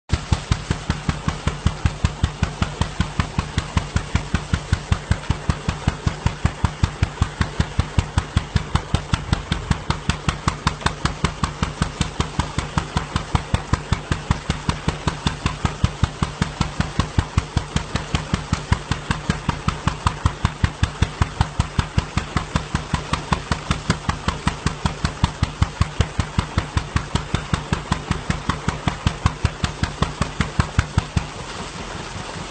نگاهم کرد:«اینم جواب سوالت، صدا از اینه، بهش میگن مکینه‌ی آب.»
makine-ab.mp3